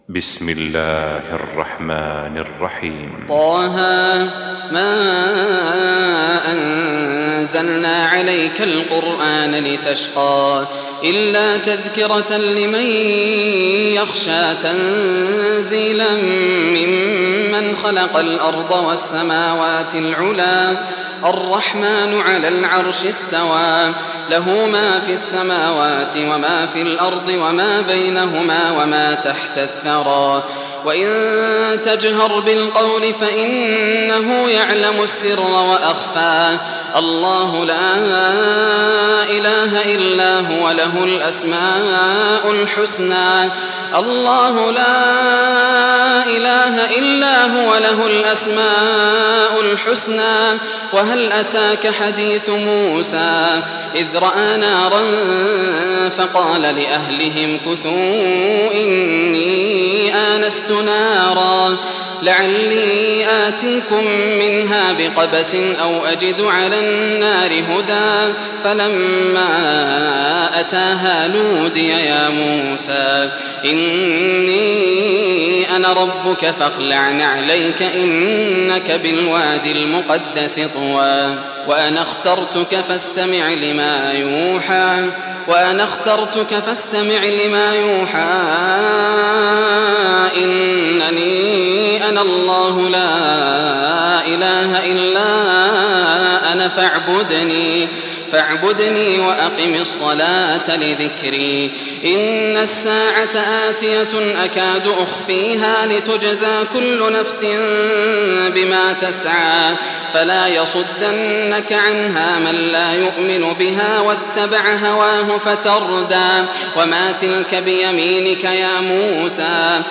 سورة طه لعام 1423 > الإصدارات > المزيد - تلاوات ياسر الدوسري